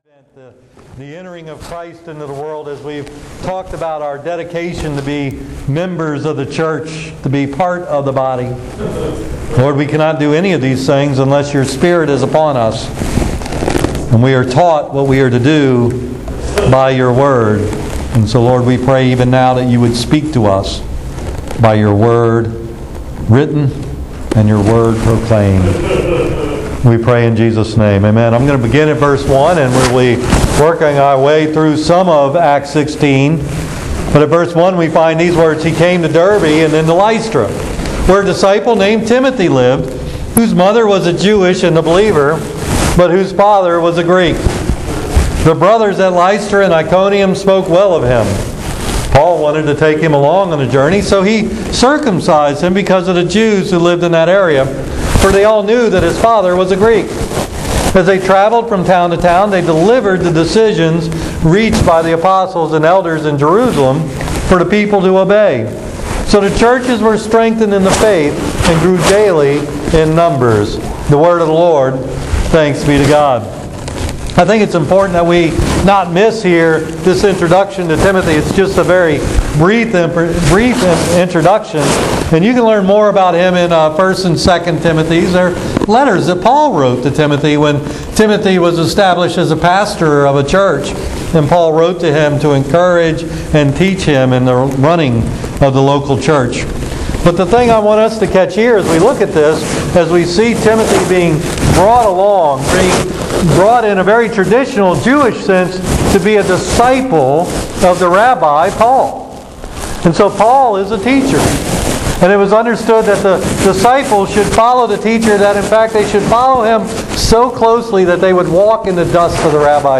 sorry for the static on this file.
sermons